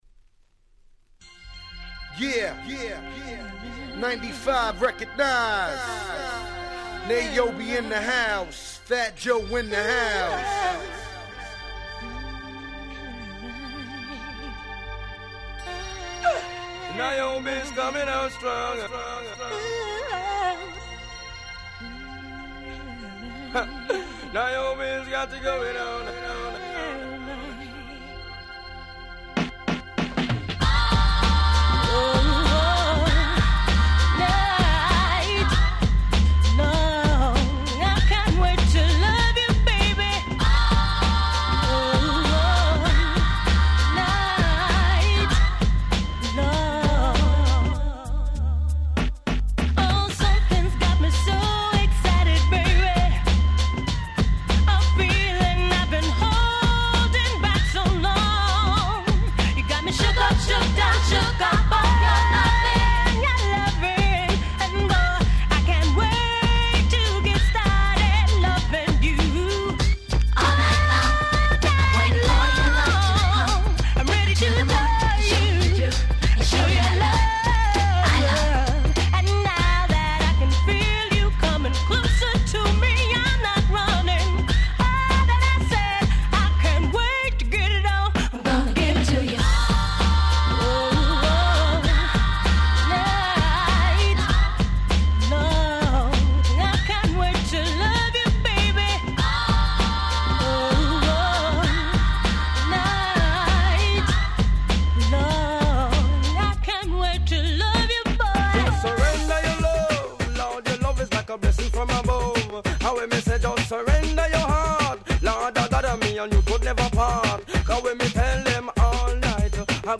Nice Hip Hop Soul♪